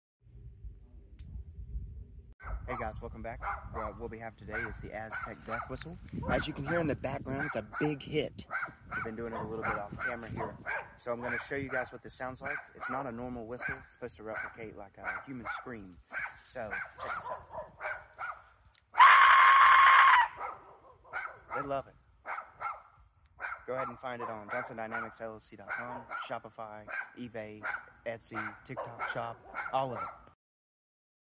Aztec Death Whistle 💀 Please sound effects free download
Aztec Death Whistle 💀 Please ignore my neighbor's dogs 😂